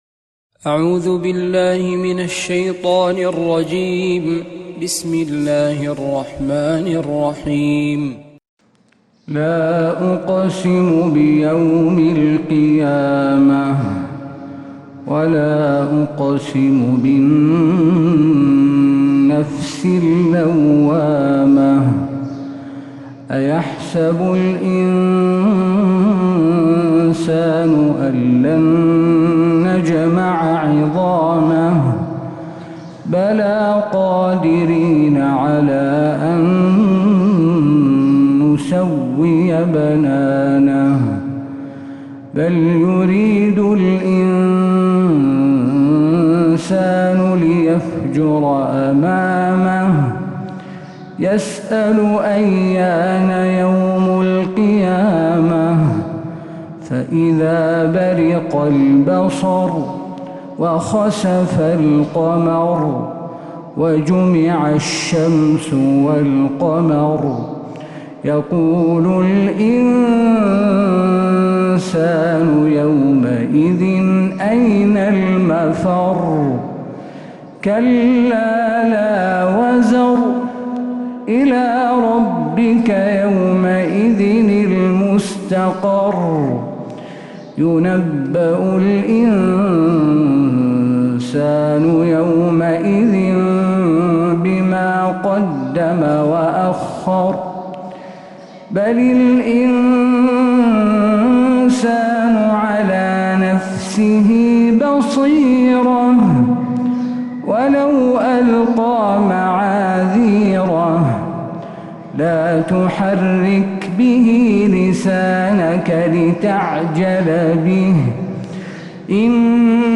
سورة القيامة كاملة من فجريات الحرم النبوي